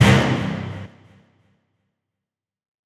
TM-88 Hit #05.wav